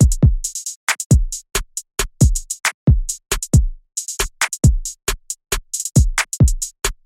陷阱大鼓
标签： 136 bpm Trap Loops Drum Loops 1.19 MB wav Key : Unknown
声道立体声